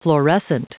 Prononciation du mot florescent en anglais (fichier audio)
Prononciation du mot : florescent